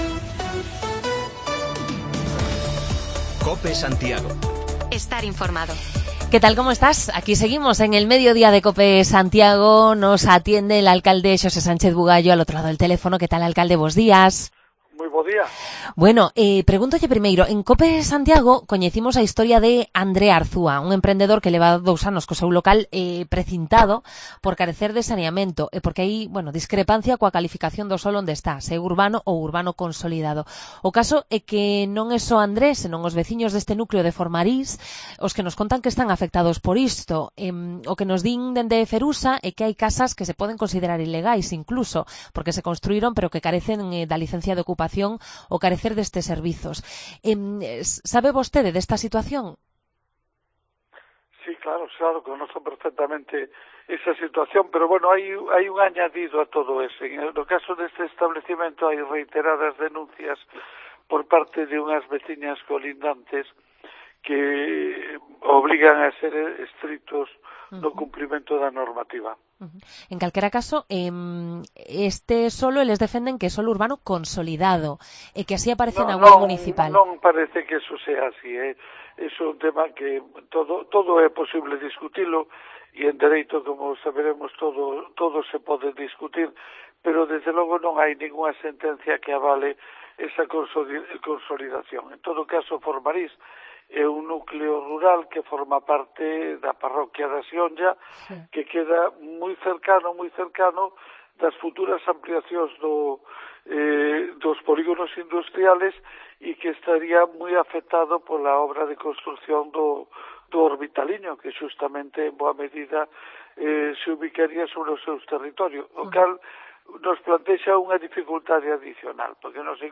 Redacción digital Madrid - Publicado el 21 mar 2023, 14:27 - Actualizado 21 mar 2023, 14:44 1 min lectura Descargar Facebook Twitter Whatsapp Telegram Enviar por email Copiar enlace Conversamos con el alcalde de Santiago, Sánchez Bugallo, sobre los asuntos más destacados en la actualidad municipal